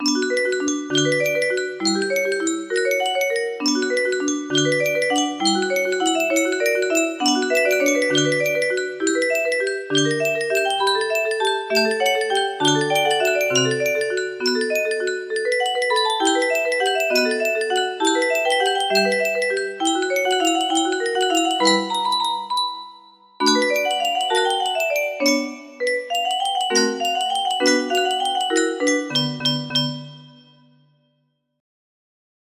José Antonio Gómez - Canción music box melody